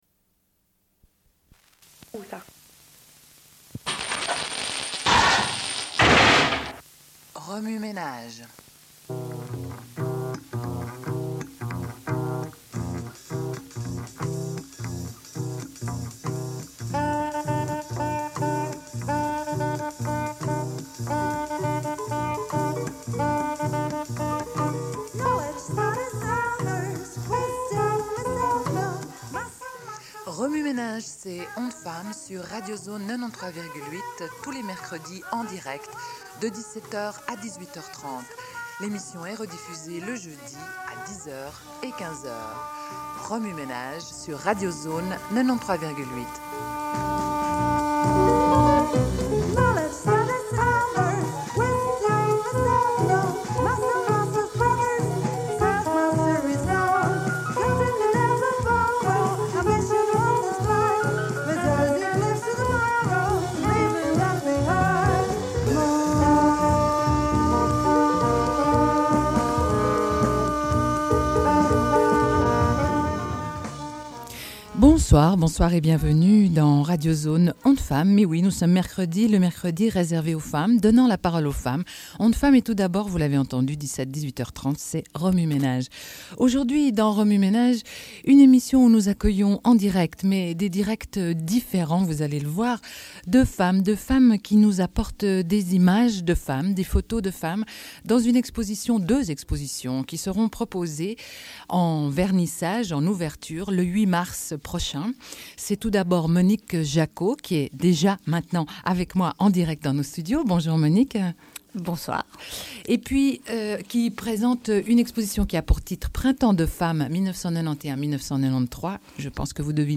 Une cassette audio, face A31:29